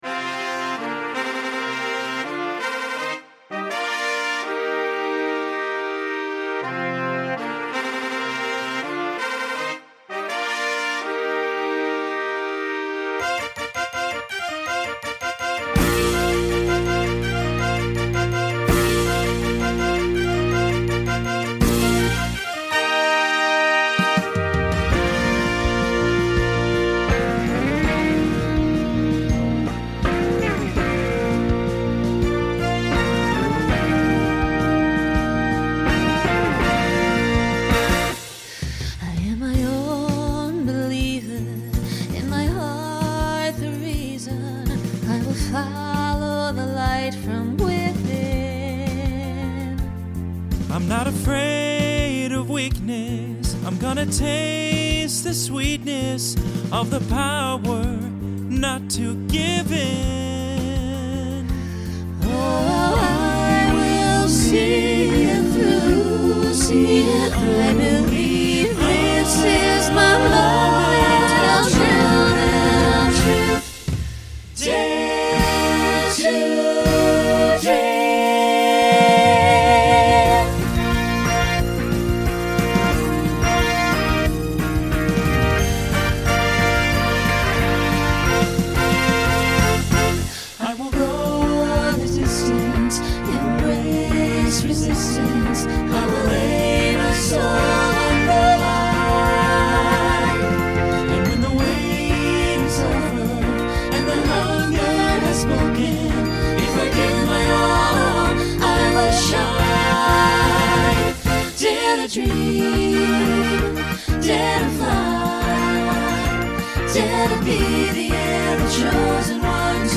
Use with or without Olympic Fanfare opening.
Genre Pop/Dance Instrumental combo
Opener Voicing SATB